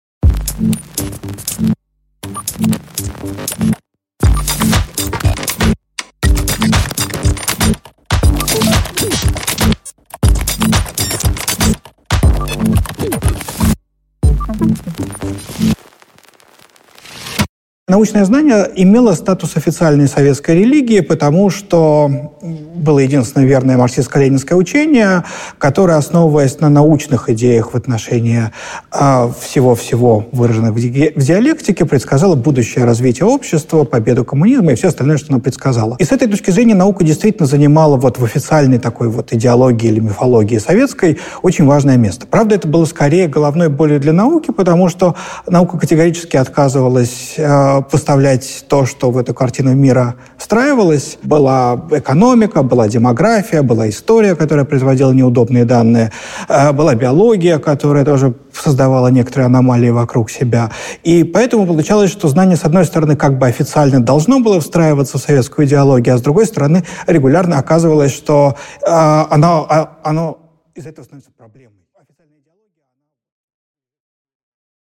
Аудиокнига Статус знания в постсоветском обществе | Библиотека аудиокниг